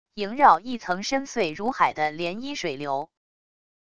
萦绕一层深邃如海的涟漪水流wav音频